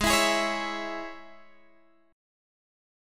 G#sus4#5 chord